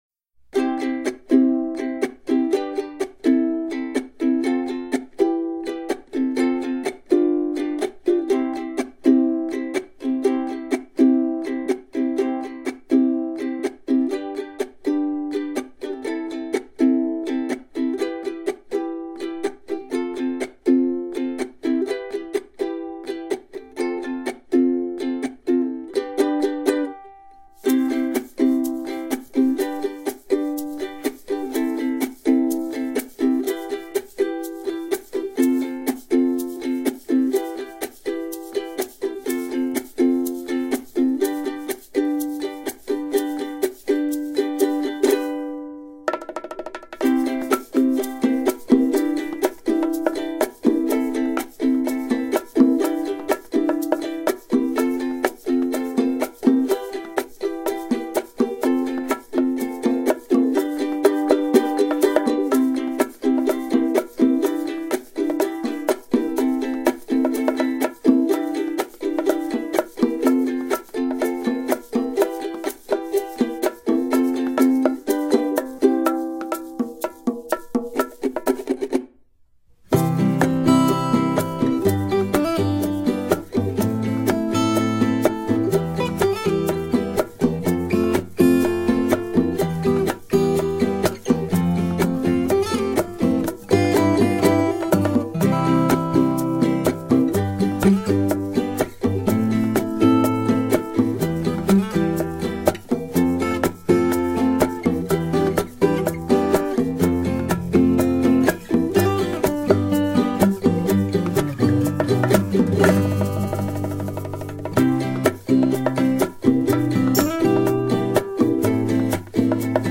C调伴奏